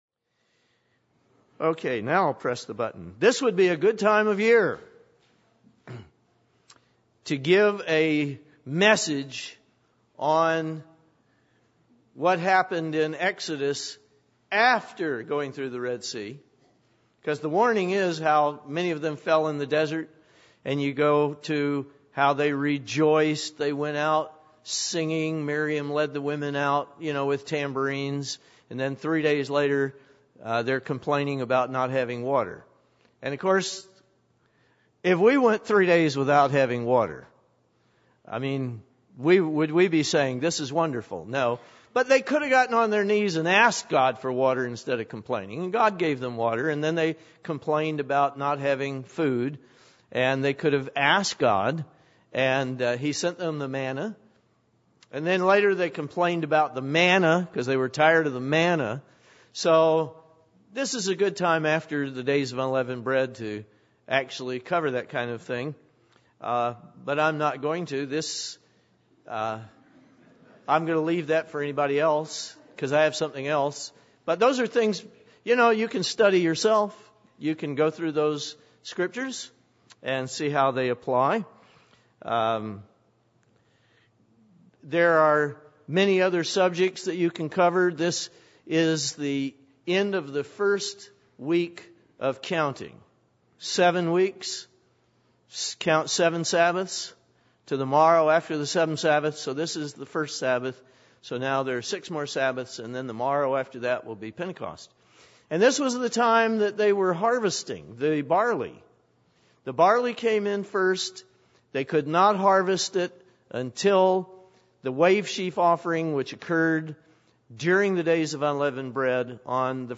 Listen to this very instructive sermon.
Given in Nashville, TN
UCG Sermon Studying the bible?